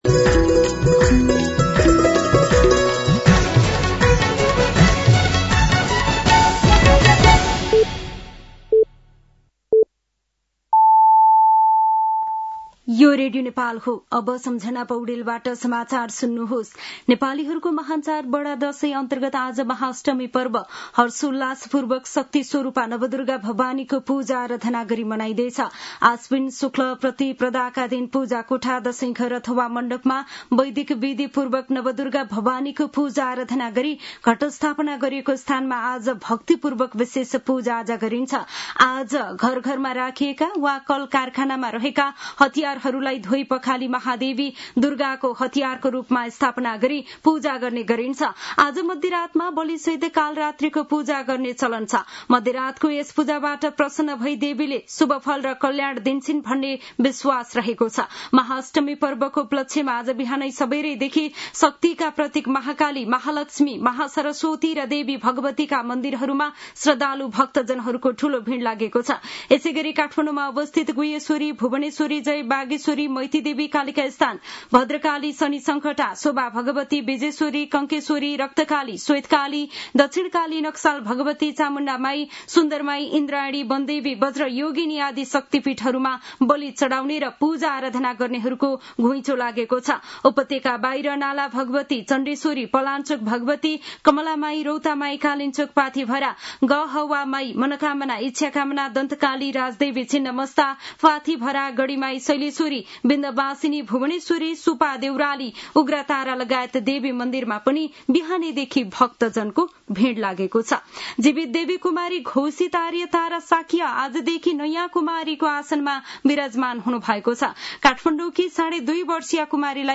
साँझ ५ बजेको नेपाली समाचार : १४ असोज , २०८२